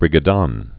(rĭgə-dŏn)